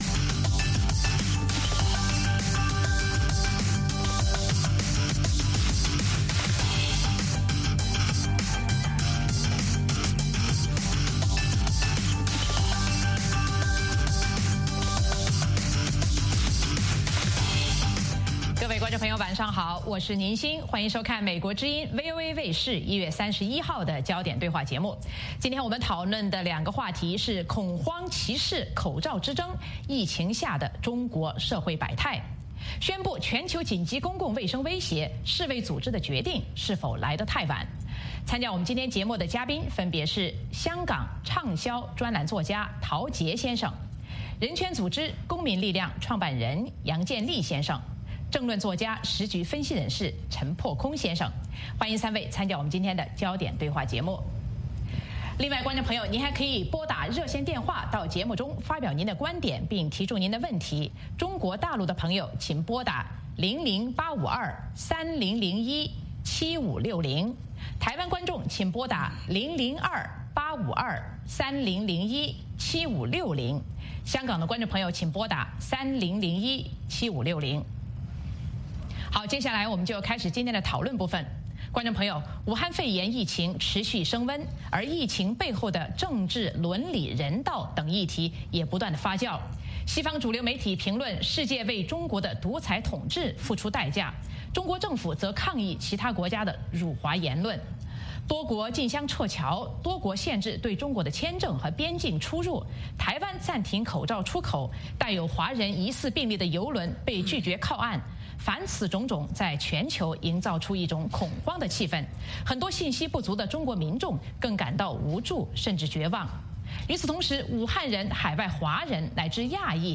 美国之音中文广播于北京时间早上6－7点重播“焦点对话”节目。《焦点对话》节目追踪国际大事、聚焦时事热点。邀请多位嘉宾对新闻事件进行分析、解读和评论。或针锋相对、或侃侃而谈。